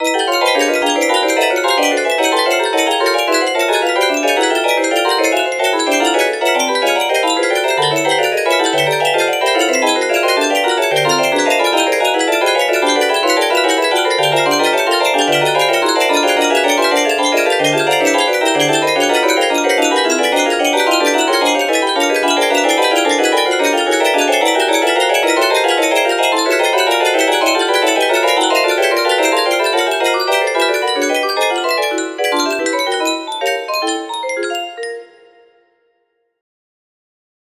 1242 music box melody